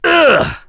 Roman-Vox_Damage_02.wav